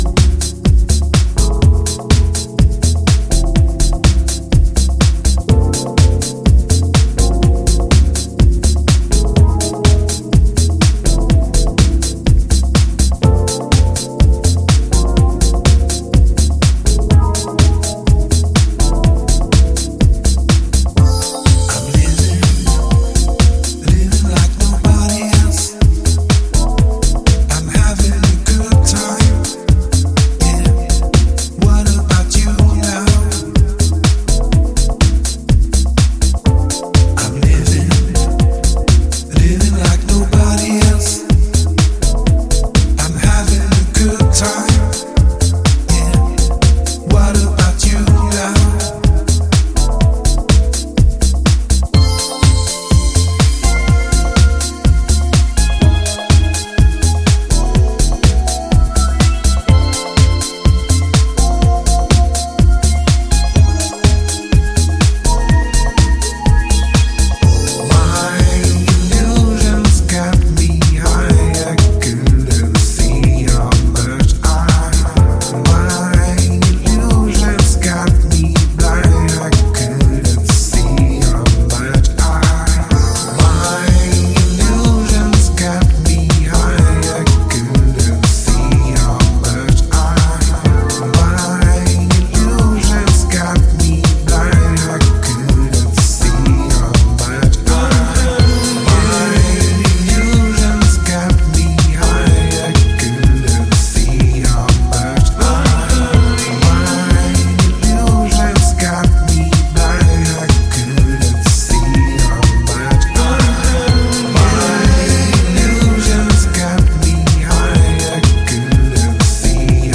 This typically soulful EP